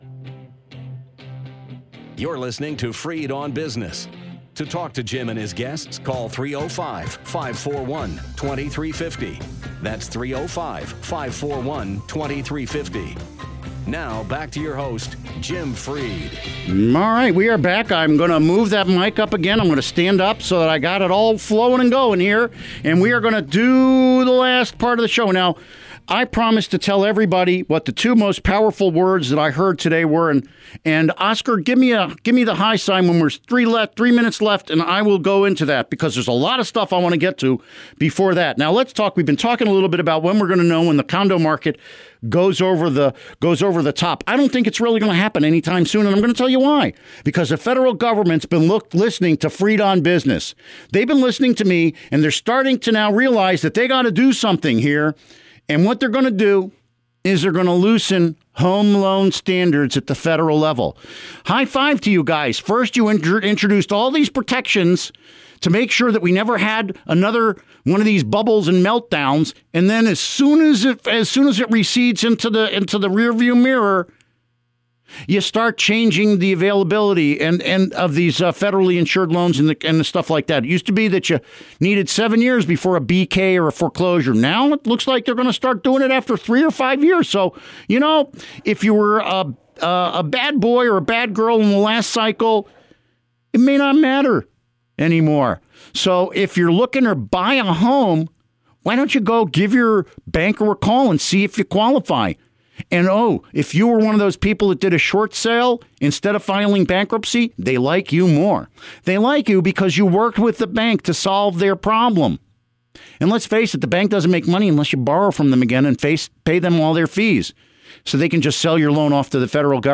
Interview Segment